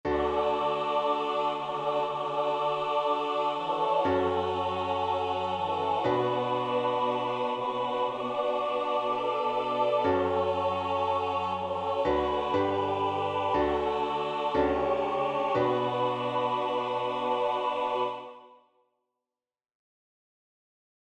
Chants de Prière universelle Téléchargé par